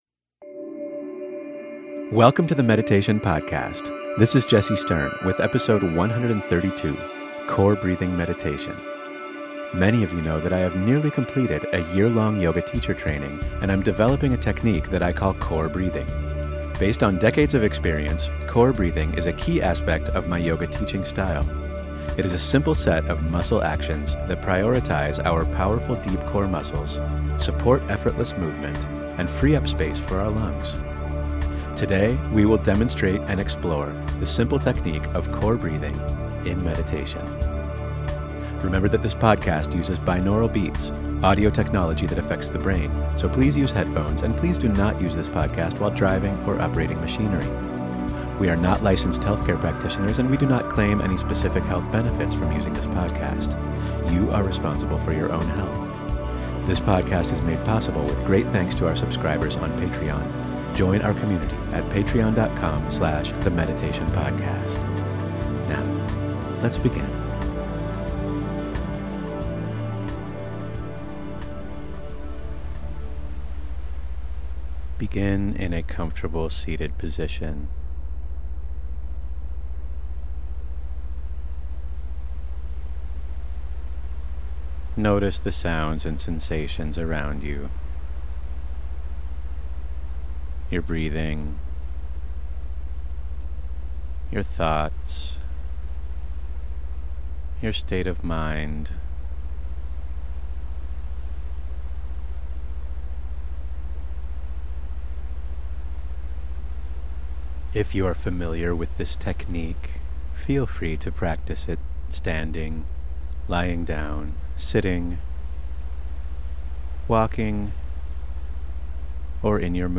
tmp132-core-breathing-meditation.mp3